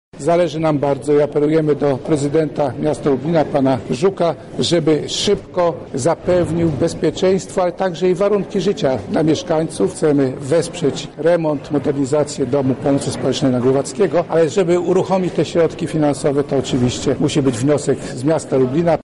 Zdajemy sobie sprawę jak wygląda teraz sytuacja naszych podopiecznych – mówi wiceminister Rodziny, Pracy i Polityki Społecznej Krzysztof Michałkiewicz: